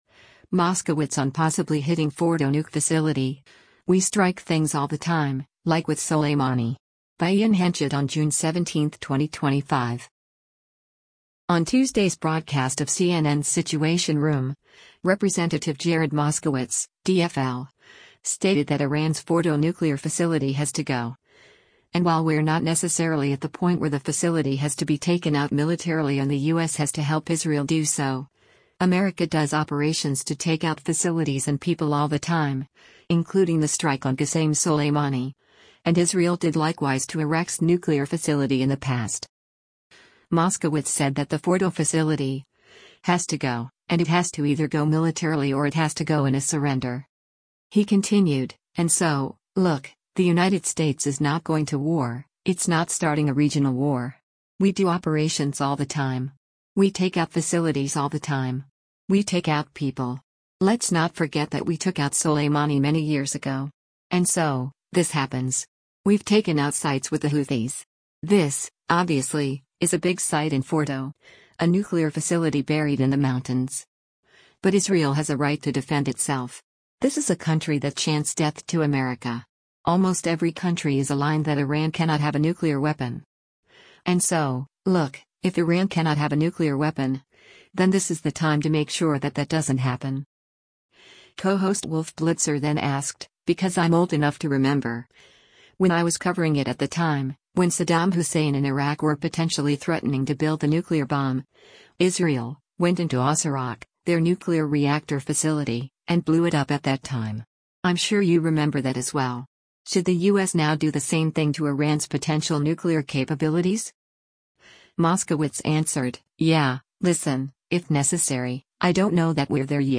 On Tuesday’s broadcast of CNN’s “Situation Room,” Rep. Jared Moskowitz (D-FL) stated that Iran’s Fordow nuclear facility has to go, and while we’re not necessarily at the point where the facility has to be taken out militarily and the U.S. has to help Israel do so, America does operations to take out facilities and people all the time, including the strike on Qasem Soleimani, and Israel did likewise to Iraq’s nuclear facility in the past.